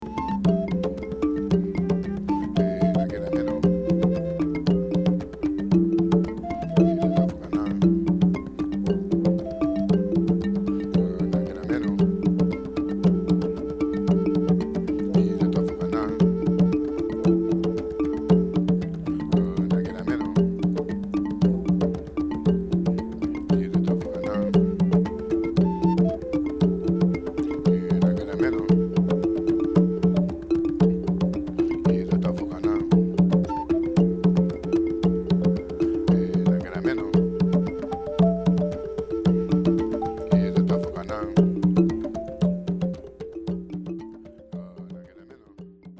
African-inspired